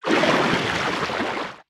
Sfx_creature_lillypaddler_swimtopose_01.ogg